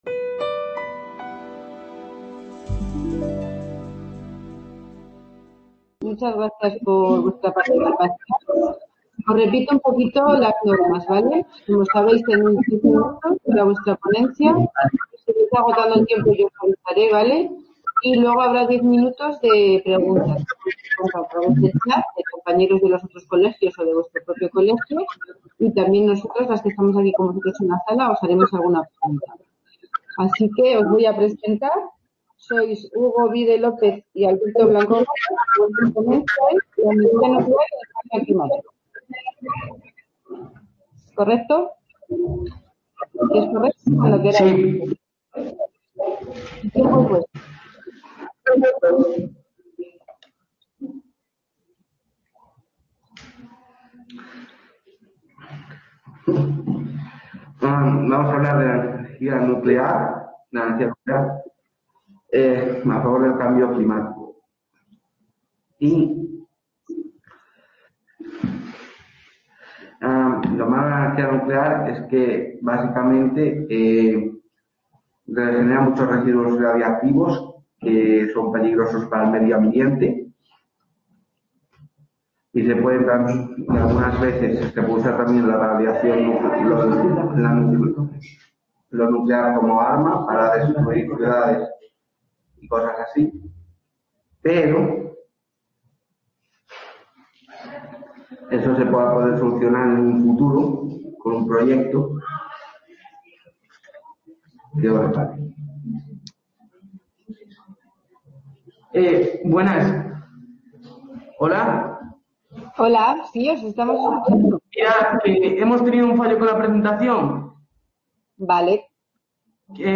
2 Edición Congreso de Jóvenes Expertos. Cambio Climático. (sala Ponferrada)